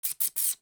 • Hamster Calls
To add to the player’s interest in controlling the hamster, I recorded a series of hamster calls using a human voice and set them to play randomly when the hamster hit the wall in each room.
Hamster_Hiss_4-1.wav